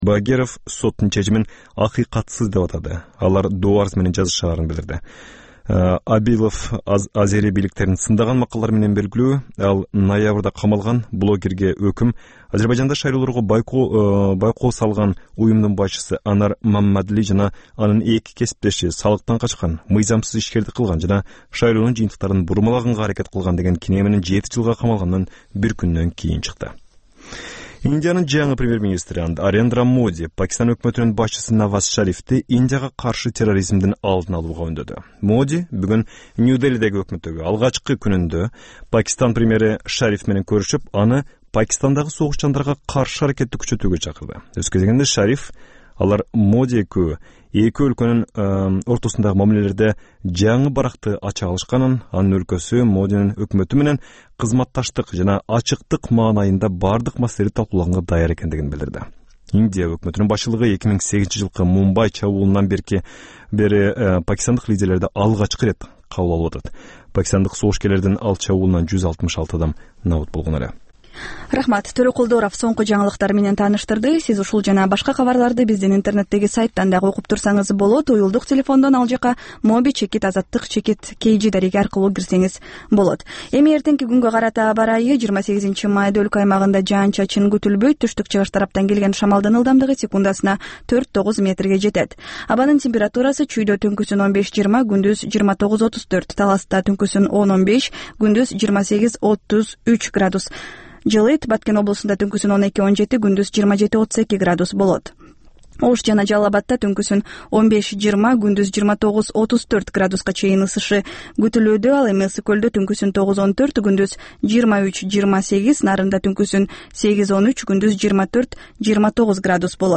"Азаттык үналгысынын" бул кечки алгачкы жарым сааттык берүүсүнүн кайталоосу жергиликтүү жана эл аралык кабарлар, репортаж, маек, аналитикалык баян, сереп, угармандардын ой-пикирлери, окурмандардын э-кат аркылуу келген пикирлеринин жалпыламасы жана башка берүүлөрдөн турат.Ар күнү Бишкек убакыты боюнча саат 22:05тен 22:30га чейин кайталанат.